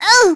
Cassandra-Vox_Damage_01.wav